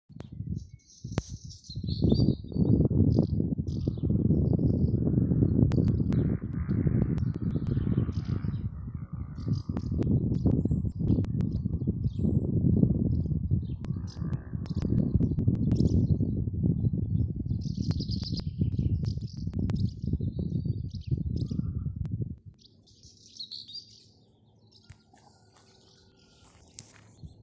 Black Redstart, Phoenicurus ochruros
Ziņotāja saglabāts vietas nosaukumsSkaistkalne
StatusSinging male in breeding season